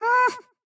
Minecraft Version Minecraft Version 25w18a Latest Release | Latest Snapshot 25w18a / assets / minecraft / sounds / mob / happy_ghast / hurt3.ogg Compare With Compare With Latest Release | Latest Snapshot
hurt3.ogg